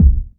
• Strong Bottom End Kick Drum Sound C Key 157.wav
Royality free kick one shot tuned to the C note. Loudest frequency: 87Hz
strong-bottom-end-kick-drum-sound-c-key-157-4Fu.wav